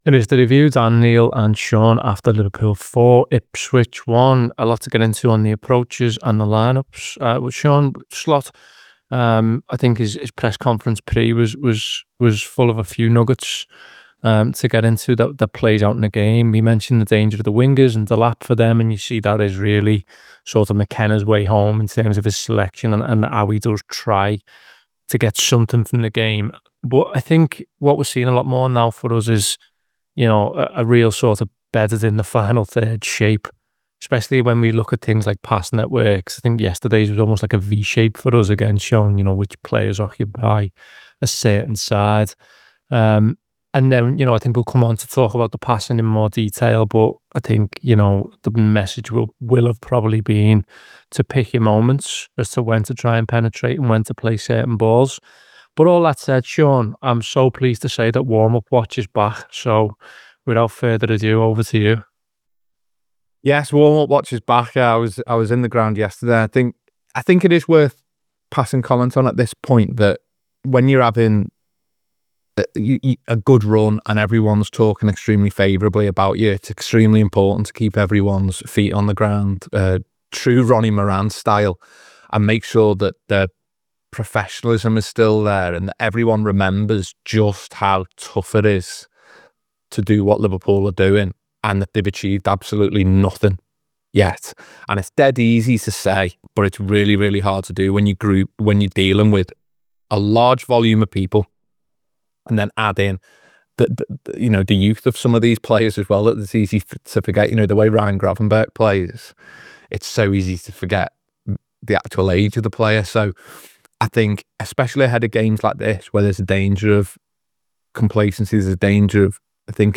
Below is a clip from the show – subscribe to The Anfield Wrap for more review chat around Liverpool 4 Ipswich Town 1…